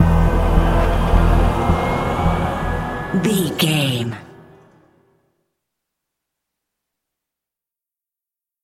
Thriller
Aeolian/Minor
E♭
Slow
synthesiser